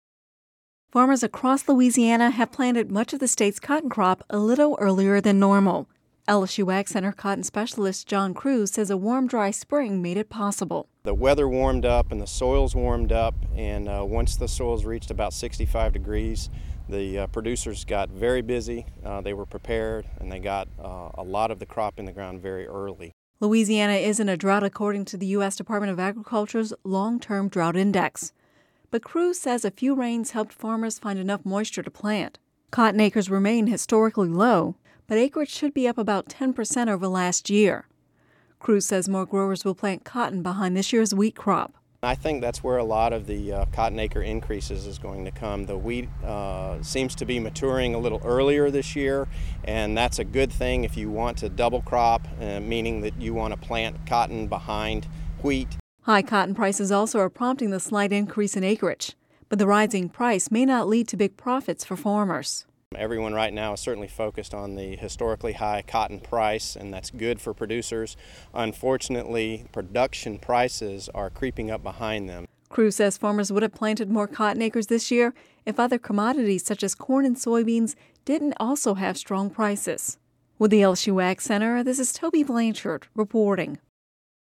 (Radio News 05/03/11) Farmers across Louisiana have planted much of the state’s cotton crop a little earlier than normal.